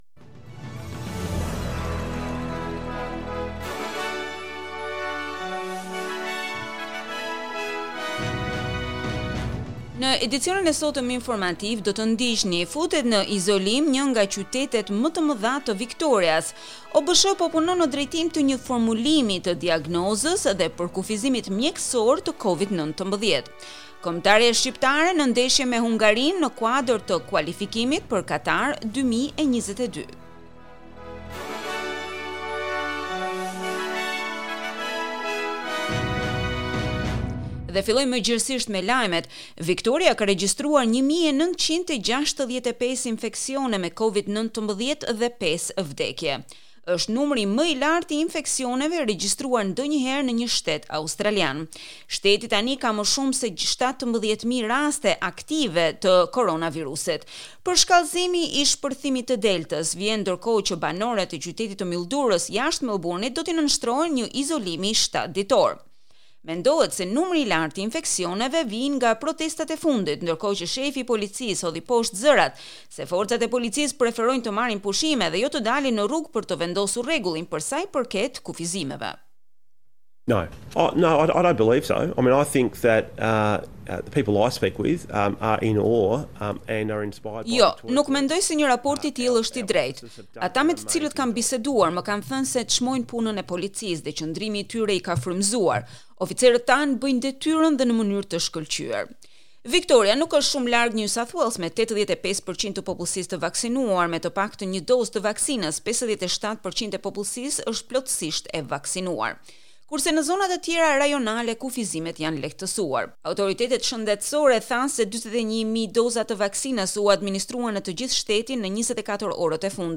SBS News Bulletin in Albanian- 9 October 2021